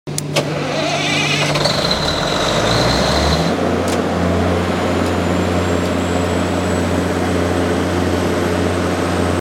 Watch as we perform a sound effects free download By generator_source 1 Downloads 1 weeks ago 9 seconds generator_source Sound Effects About Watch as we perform a Mp3 Sound Effect Watch as we perform a load bank test on this CAT 400kW diesel generator. Tested to meet our 31-Point Quality Assurance Certification and ready for its next project.